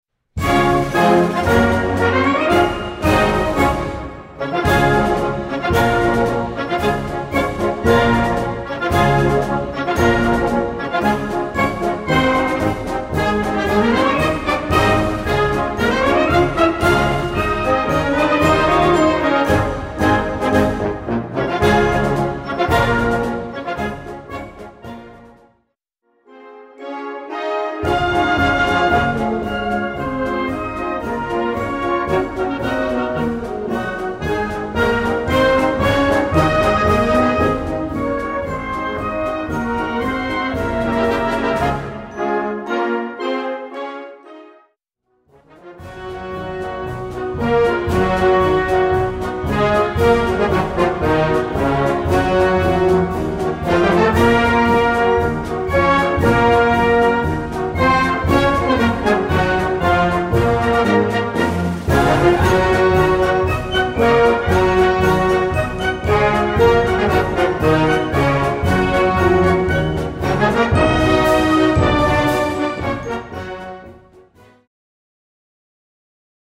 Gattung: Marsch
A5-Quer Besetzung: Blasorchester PDF